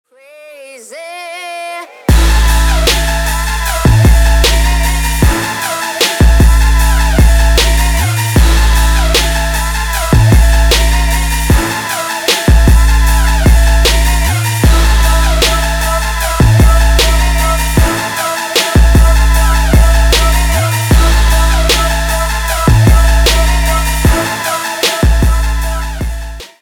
• Качество: 320, Stereo
восточные мотивы
женский голос
Electronic
EDM
Trap
Качающий отрывок в стиле трэп